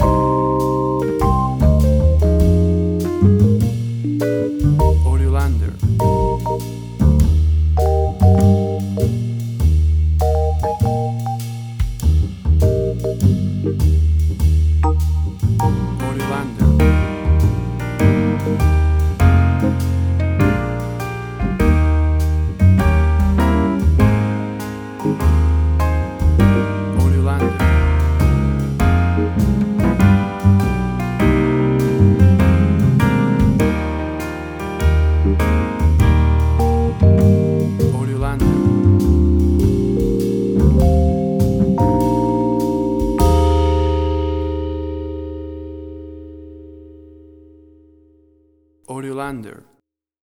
Full of happy joyful festive sounds and holiday feeling!.
Tempo (BPM): 100